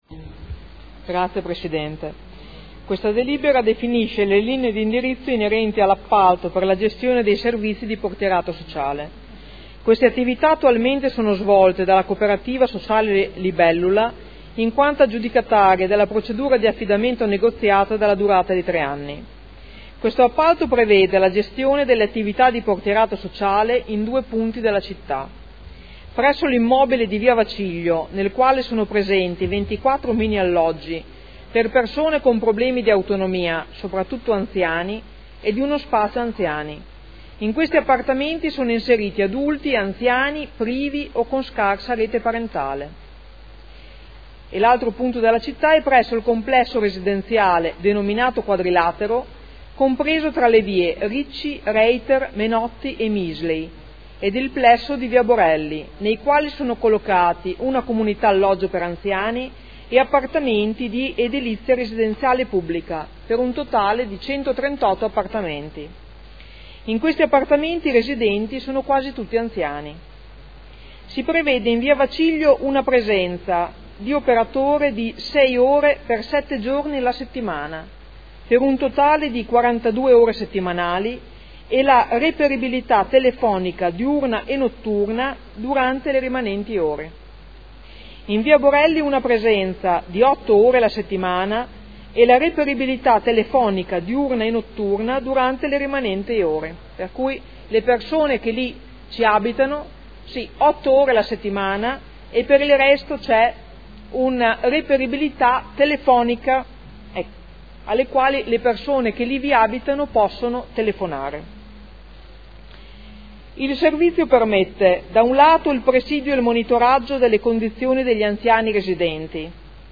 Francesca Maletti — Sito Audio Consiglio Comunale